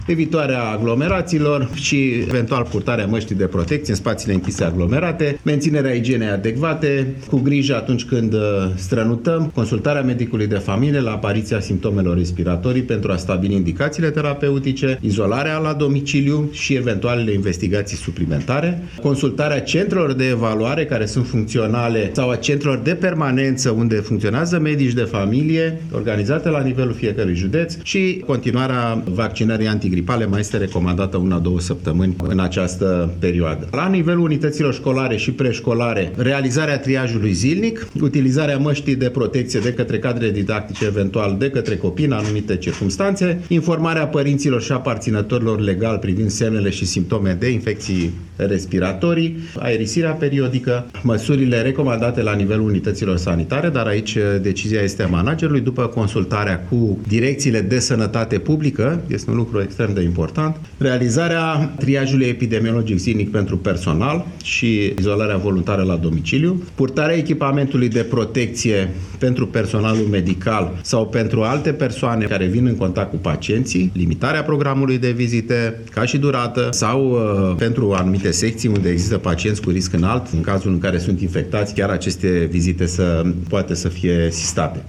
Ministrul Sănătății, Alexandru Rafila.